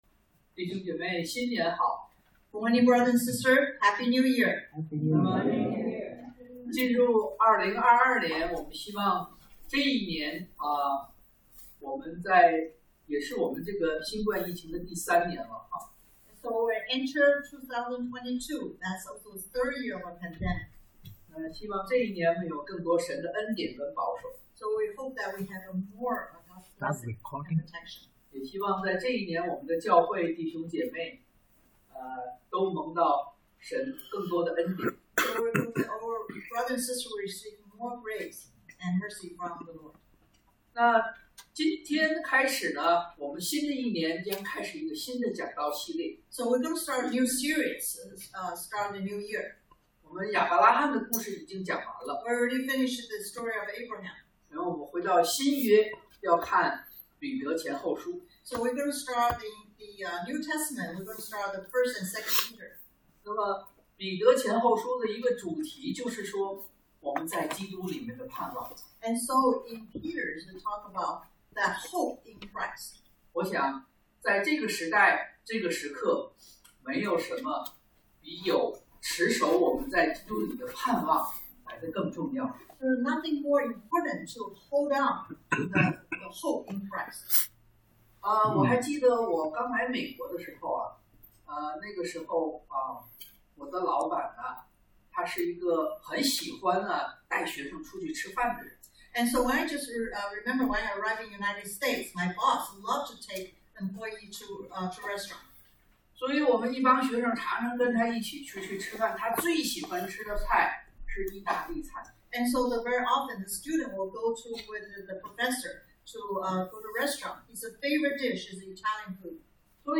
彼前1 Peter 1:1-12 Service Type: Sunday AM 不滅的盼望 A Hope that Never Dies Passage